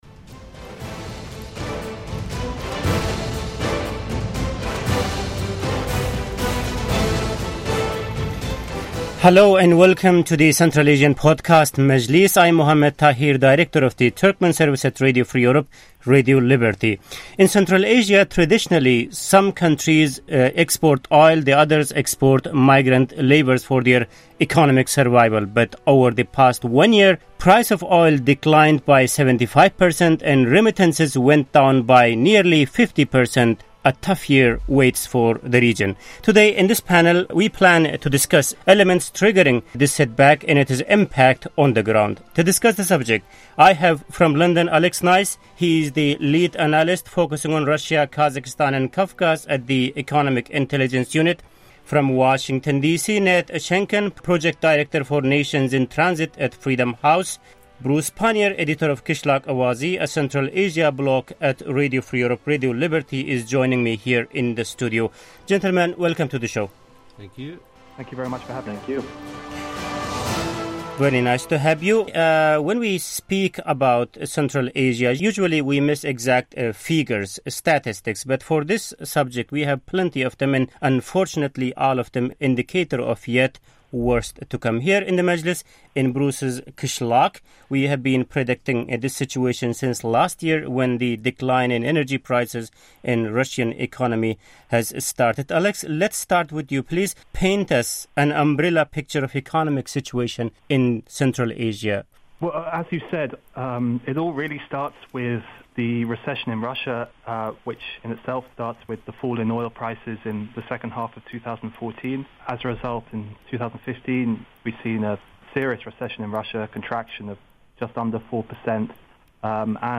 The writing has been on the wall for Central Asia for months now -- an economic crisis was coming to Central Asia and there was no way of avoiding it. To discuss this new reality of Central Asia, today and for the foreseeable future, RFE/RL's Turkmen Service assembled a panel discussion.